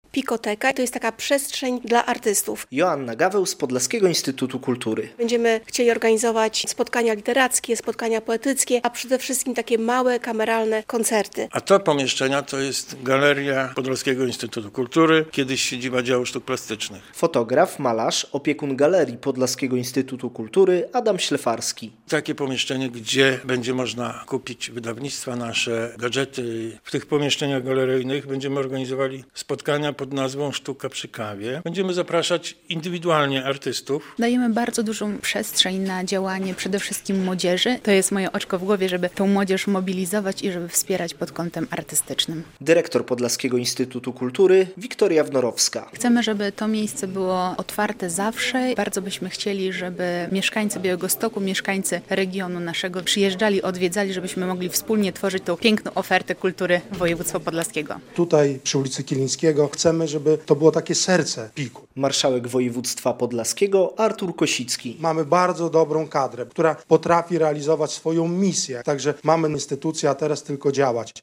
Budynek Podlaskiego Instytutu Kultury przy ul. Kilińskiego jest już otwarty po remoncie - relacja